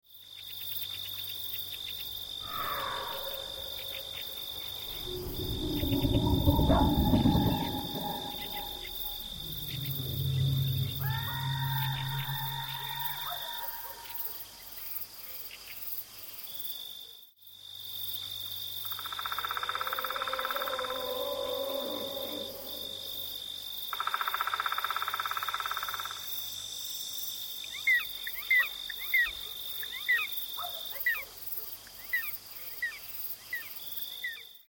Scary Night Ambience Sound Button - Free Download & Play